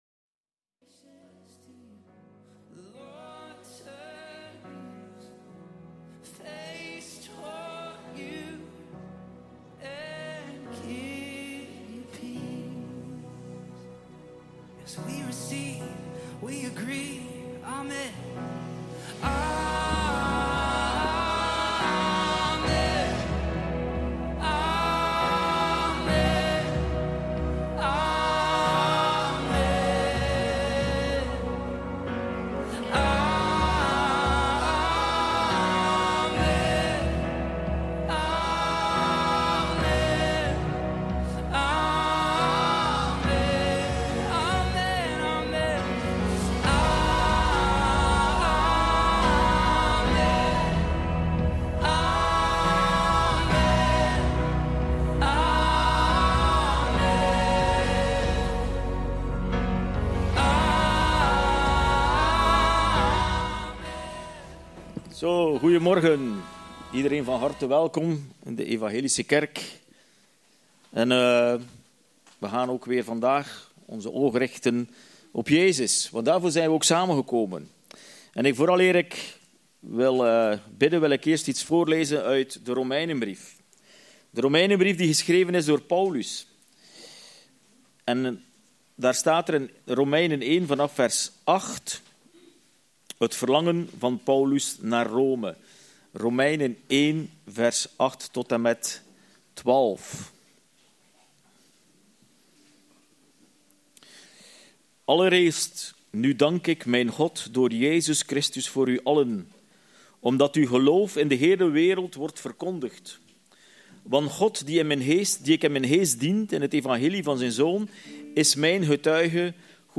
– Evangelische Kerk De Pottenbakker VZW